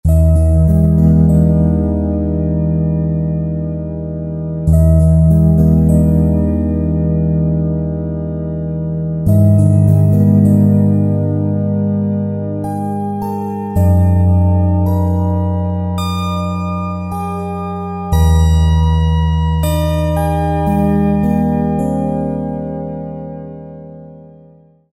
Marvelous synthesizer... probably inspired by the character of older ARP instruments.